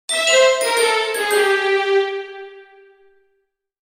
Game Level Fail Sound Effect
The failure melody plays when a player fails to complete a level or objective in a video game, signaling the need to try again. Game over music.
Game-level-fail-sound-effect.mp3